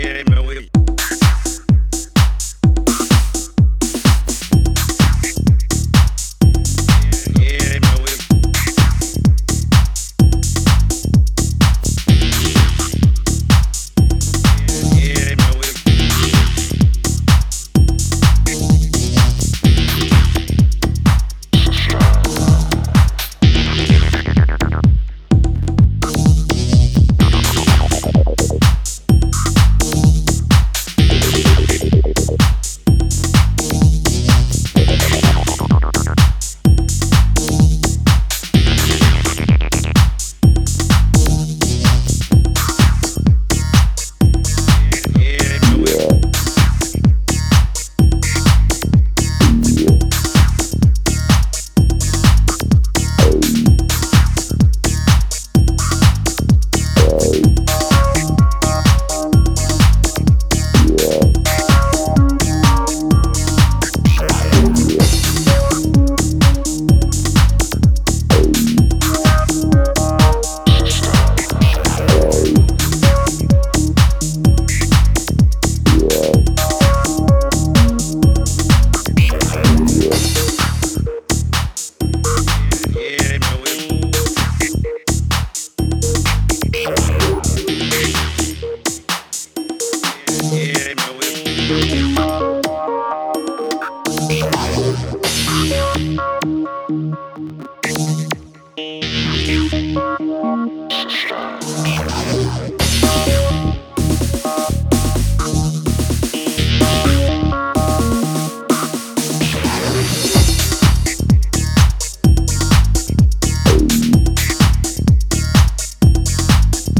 blissed-out, iconic house